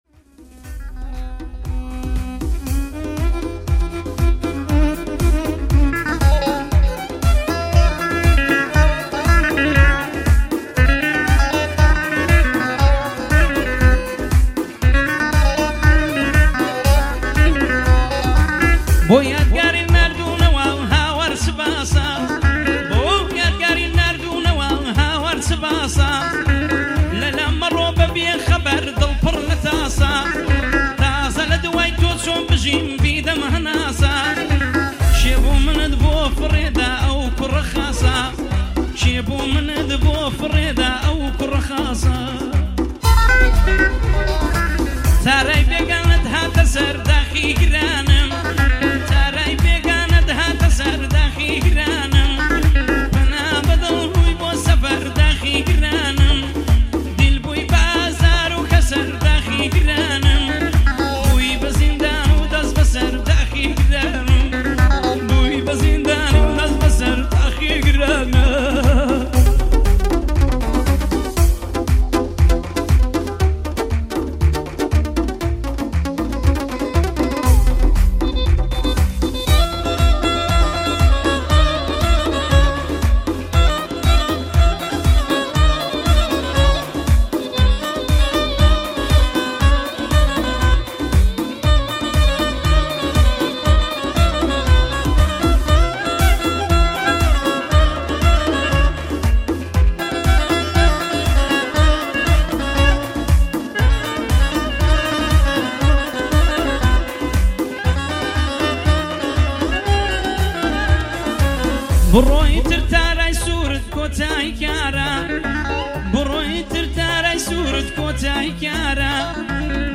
رقص کردی